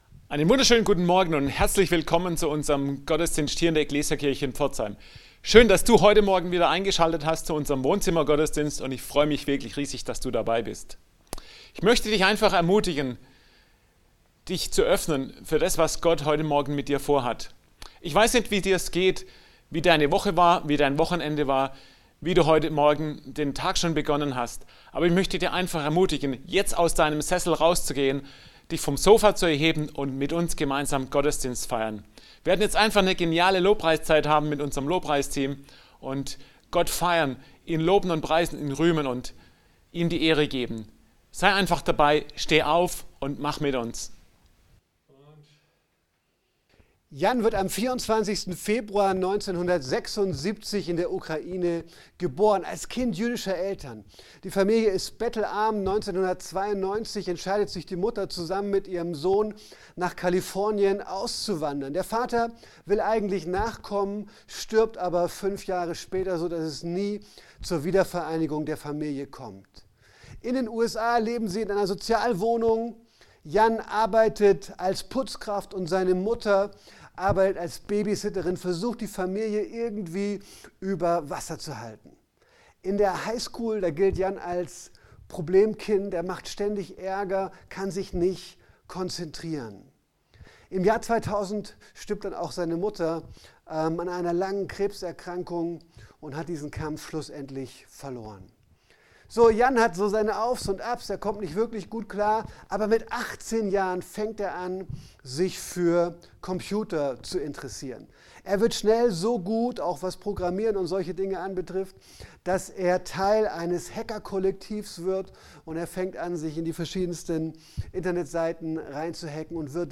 Mai 2020 AUDIO Online Gottesdienst Promise Keeper